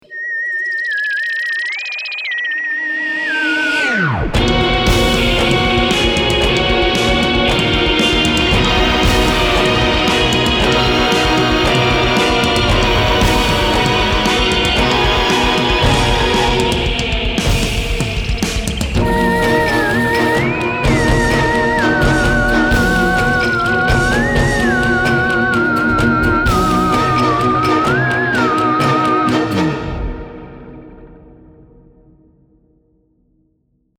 BA_30_epic_music.mp3